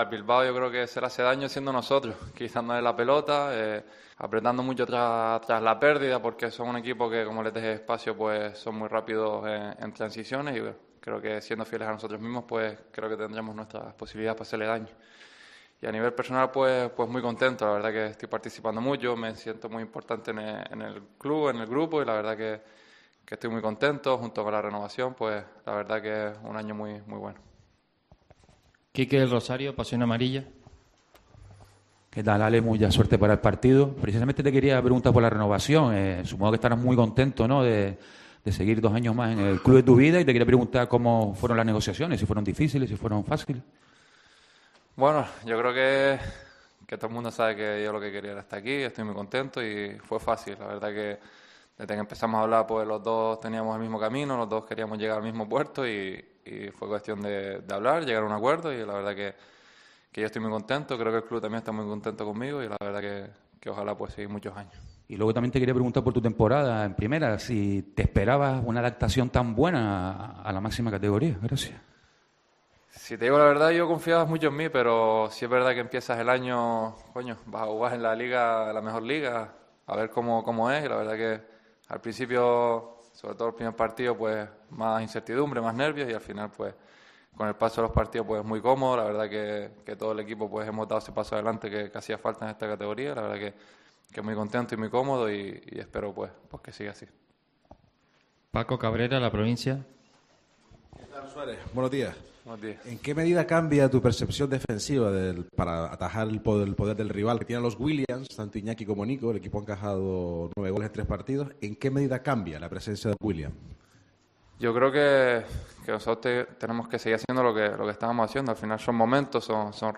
El defensa del equipo grancanario analiza el partido de este domingo ante el Athletic
El defensa de la UD Las Palmas Álex Suárez compareció para analizar el encuentro de este domingo, a las 15.15 horas, ante el Athletic en el Estadio Gran Canaria.